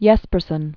(yĕspər-sən), (Jens) Otto (Harry) 1860-1943.